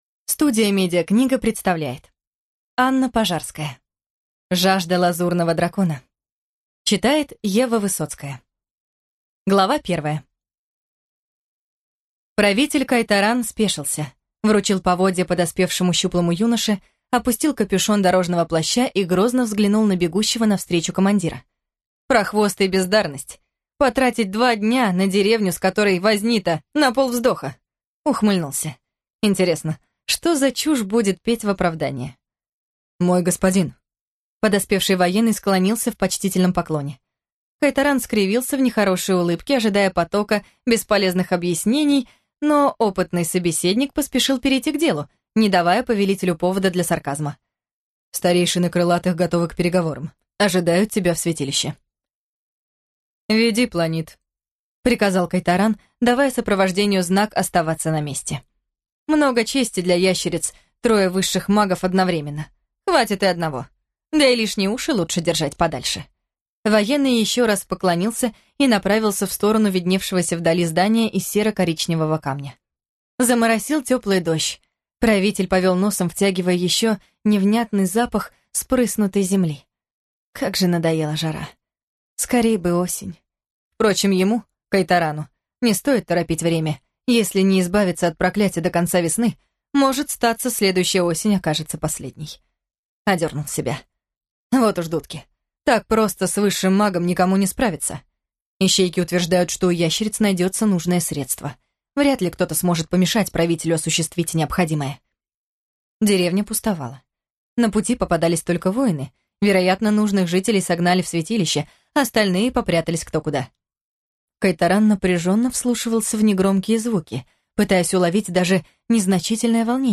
Аудиокнига Жажда лазурного дракона | Библиотека аудиокниг